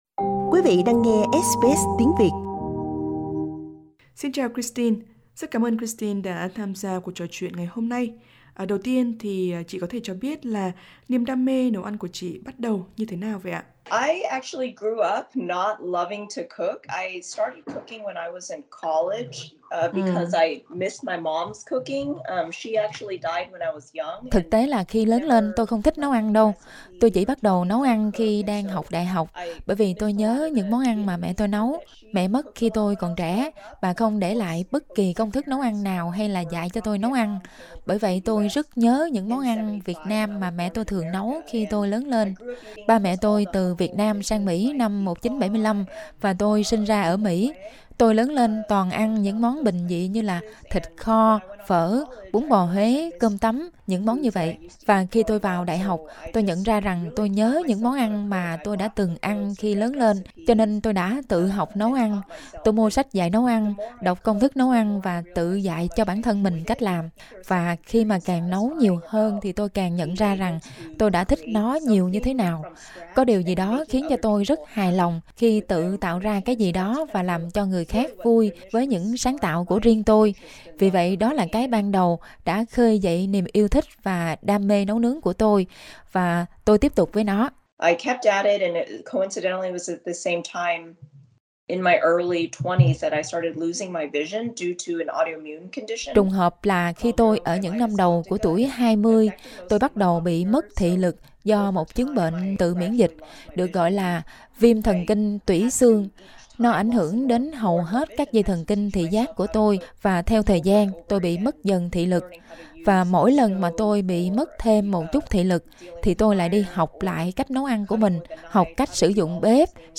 Hành trình của Christine đến với Masterchef như thế nào, và cuộc sống của một đầu bếp khiếm thị ra sao? SBS Tiếng Việt trò chuyện với Christine Hà - để nghe câu chuyện của cô gái với tài năng và nghị lực tuyệt vời này.